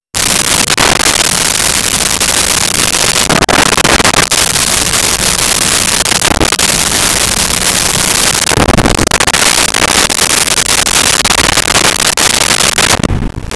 Alligator Growls 01
Alligator Growls 01.wav